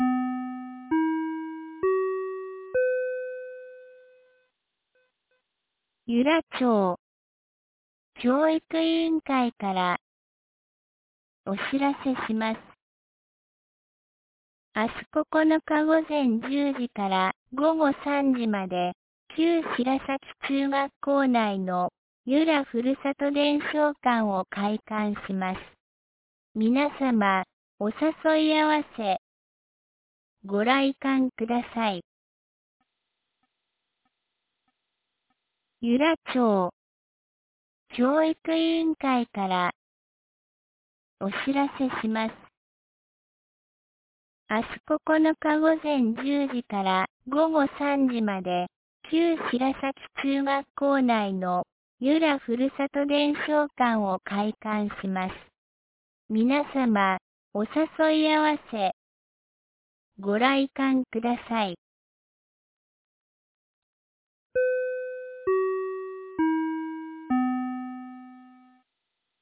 2025年11月08日 17時11分に、由良町から全地区へ放送がありました。